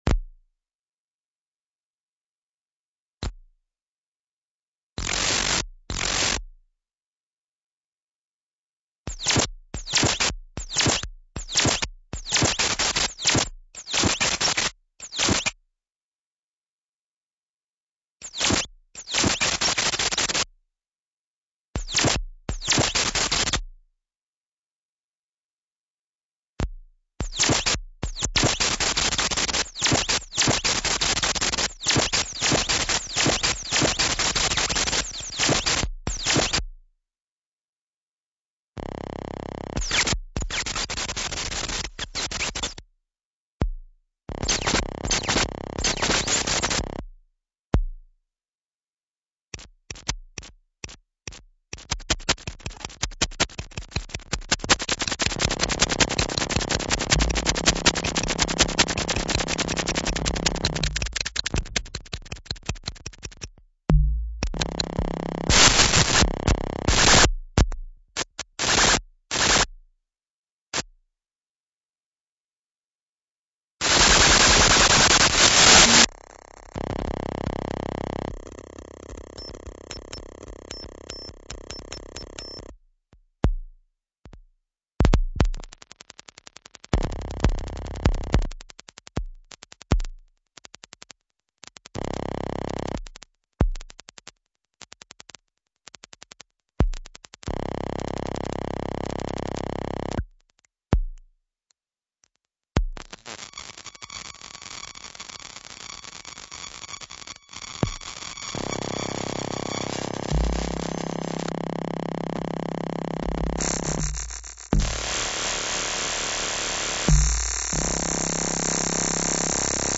recorded at atelier algorythmics /